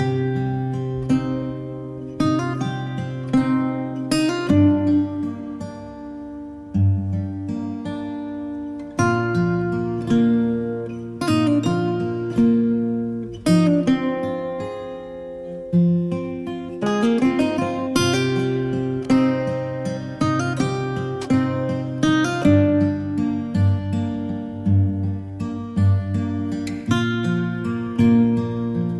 موزیک زنگ غمگین